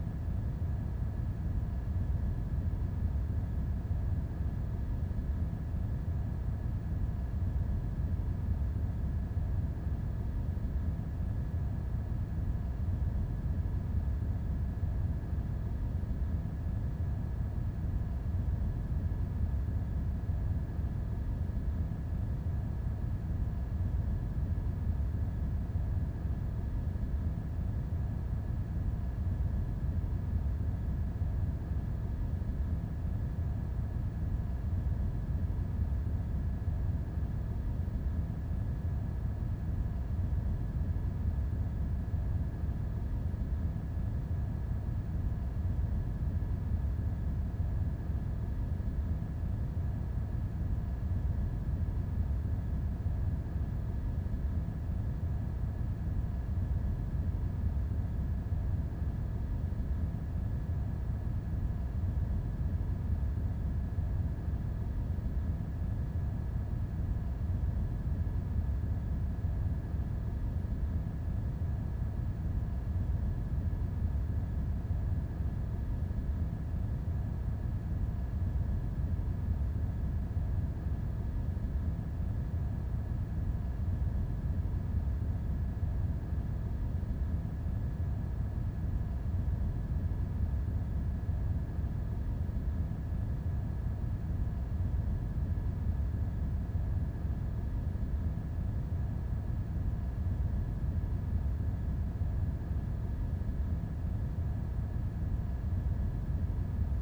DeltaVR/Ambience, Hitech, Computer Lab, Server Room, Hum 02 SND43180.wav at 187c700f9c9718e8b1c2ec03772d35e772aebf9e